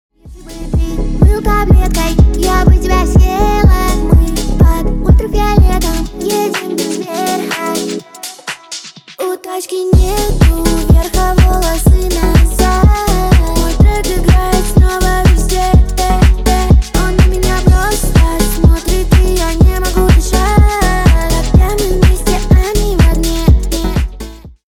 • Качество: 320, Stereo
красивый женский вокал